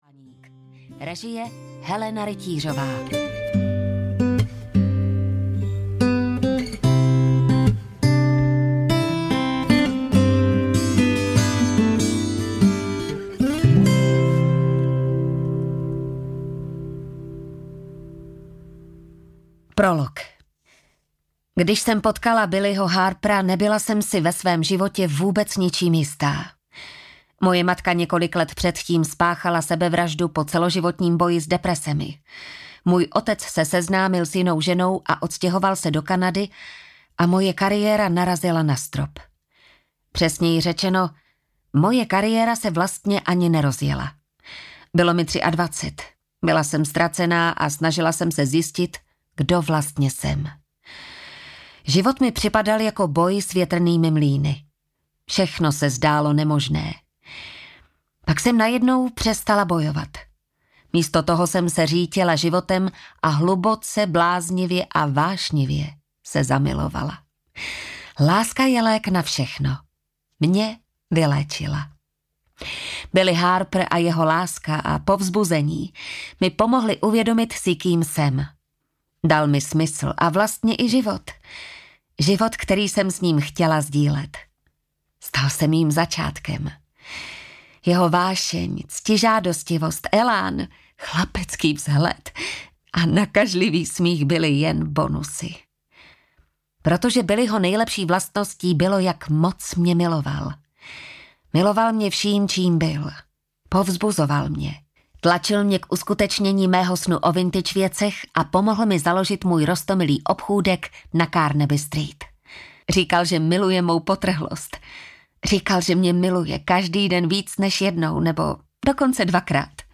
Pro tebe audiokniha
Ukázka z knihy